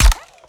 GUNAuto_RPU1 Fire_03_SFRMS_SCIWPNS.wav